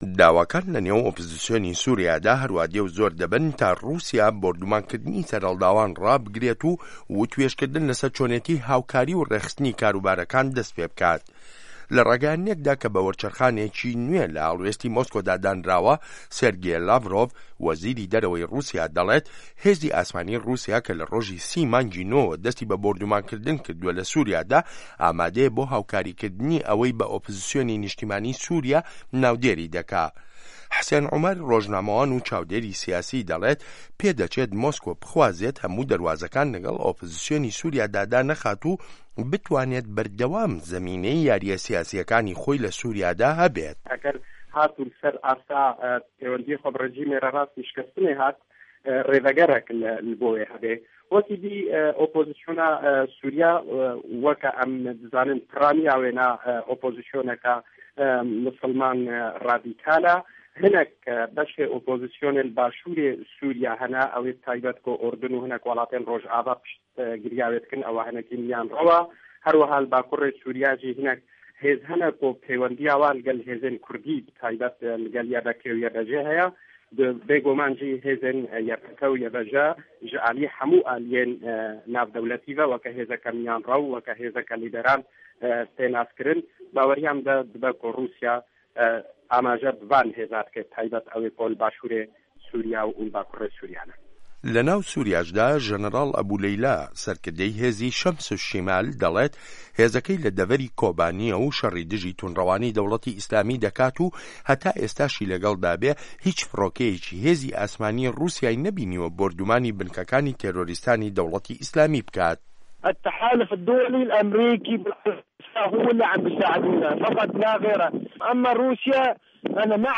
ڕاپۆرتی ڕوسیا و ئۆپزسیۆنی سوریا